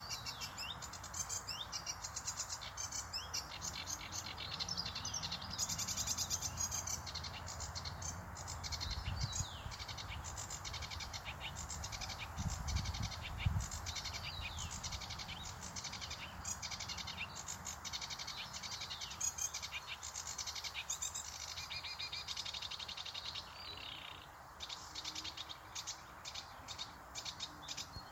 Putni -> Ķauķi ->
Ceru ķauķis, Acrocephalus schoenobaenus
StatussDzied ligzdošanai piemērotā biotopā (D)